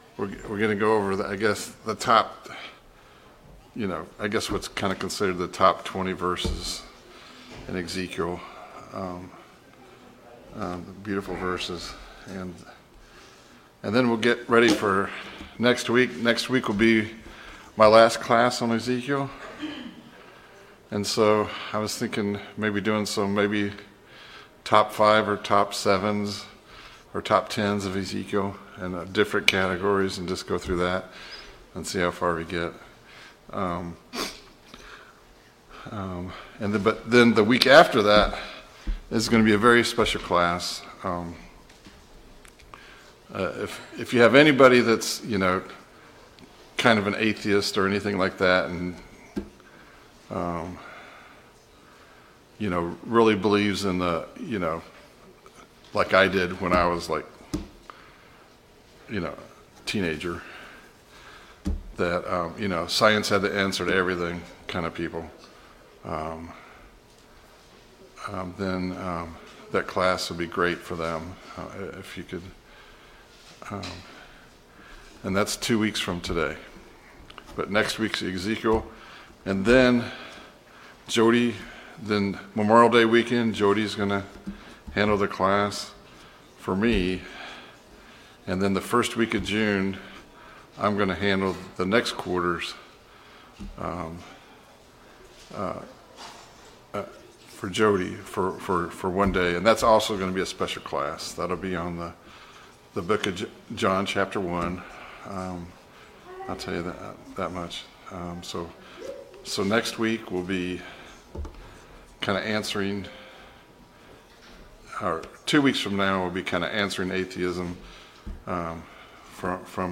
Study of Ezekiel Service Type: Sunday Morning Bible Class « Study of Paul’s Minor Epistles